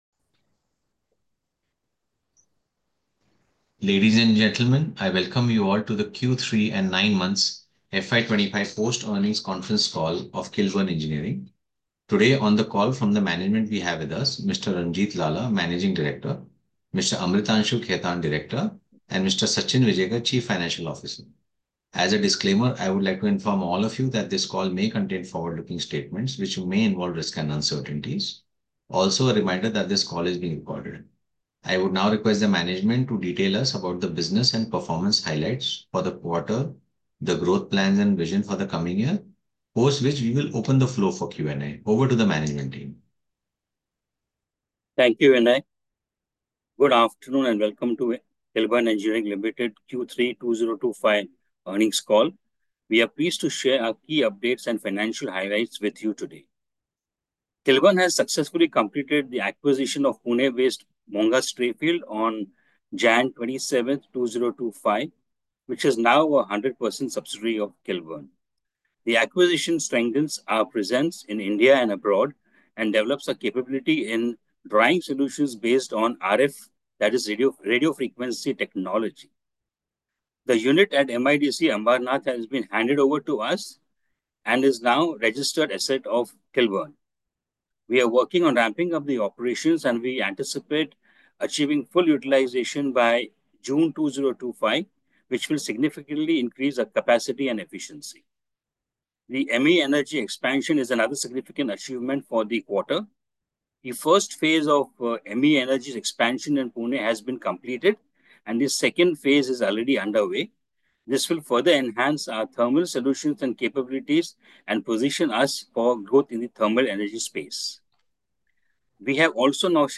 Recordings of Investor Concall - Kilburn Engineering Ltd